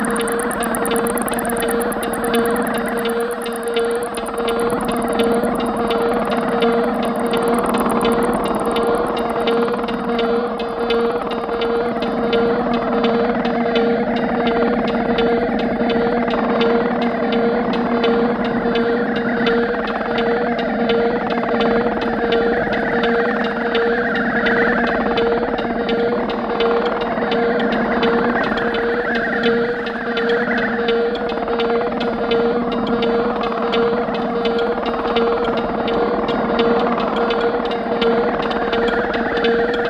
Electronic
hi-definition stereo release